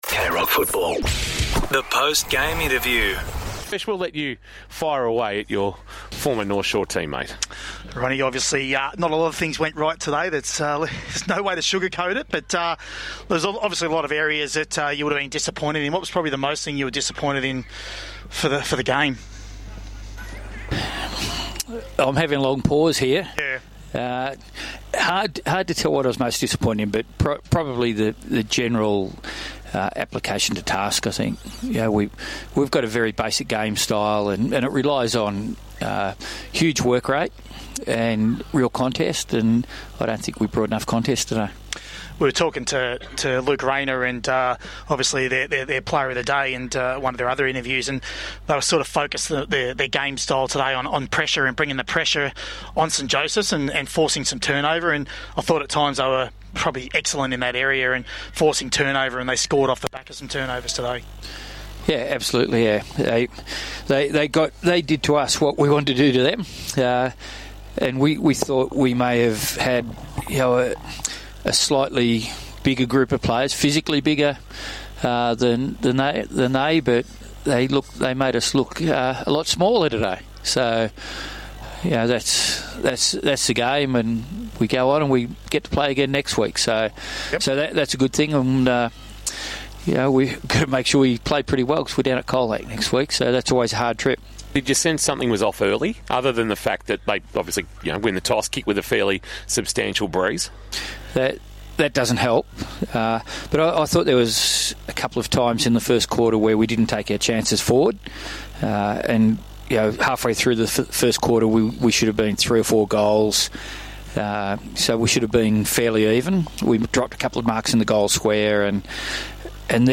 Post-match interview